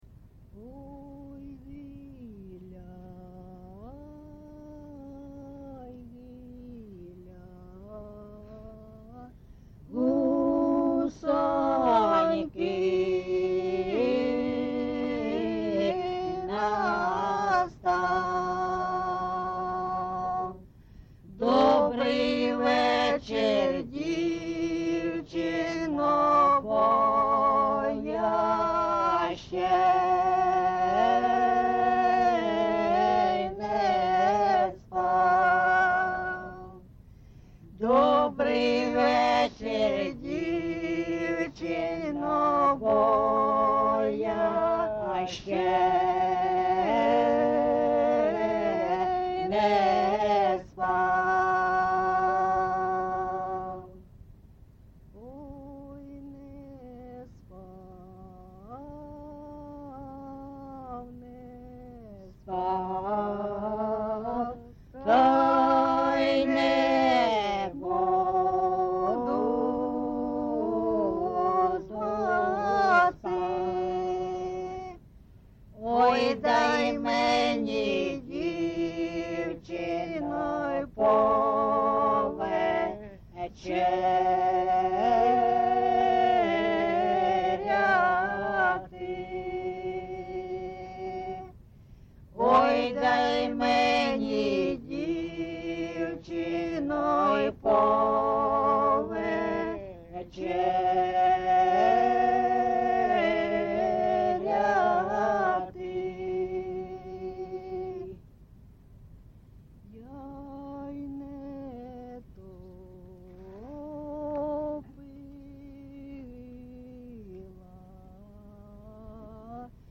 ЖанрПісні з особистого та родинного життя
Місце записус. Андріївка, Великоновосілківський район, Донецька обл., Україна, Слобожанщина